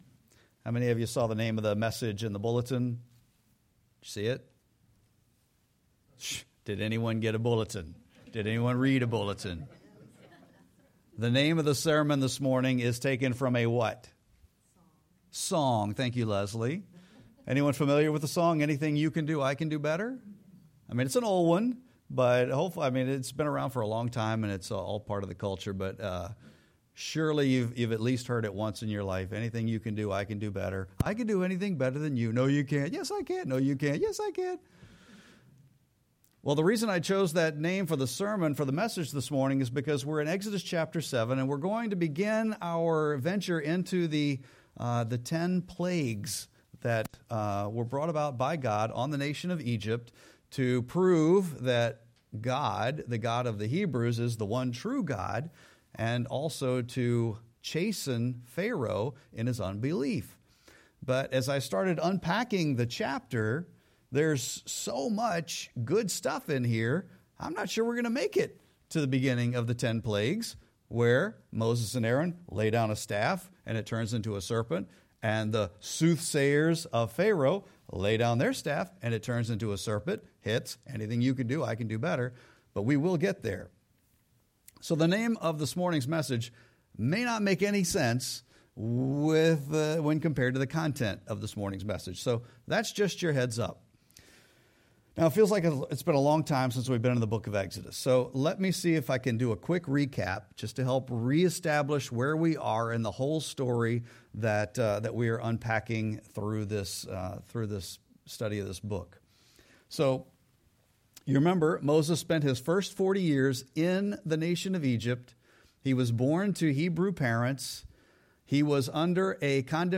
Sermon-4-12-26.mp3